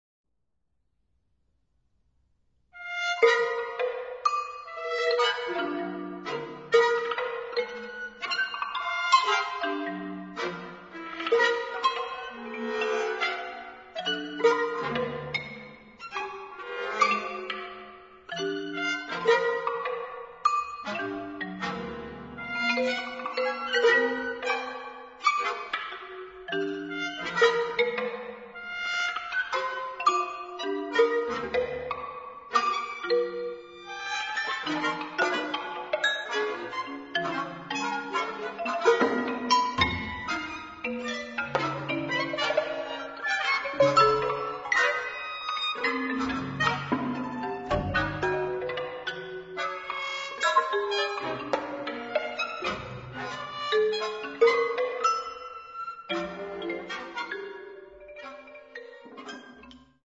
Clarinet
Flute
French Horn
Harp
Marimba
Oboe
Percussion
Strings
Trombone
Trumpet
uses a quarter tone marimba with extension
Rossyln hill Chapel